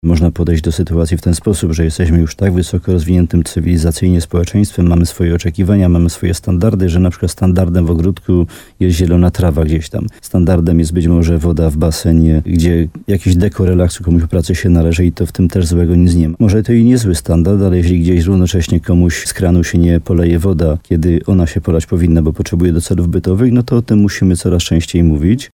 Jak mówił wójt Jan Dziedzina w programie Słowo za Słowo na antenie RDN Nowy Sącz, trzeba szanować wodę pitną, zarówno z prywatnych studni, jak i gminnego wodociągu. Szczególnie w upały nie powinno się podlewać trawników czy wypełniać nią basenów.